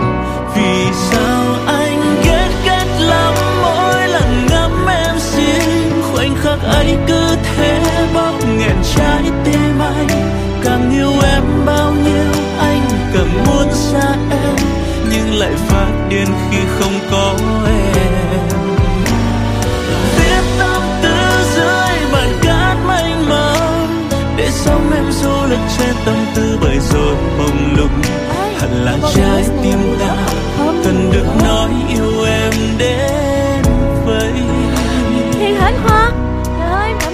thuộc dòng Nhạc Trẻ.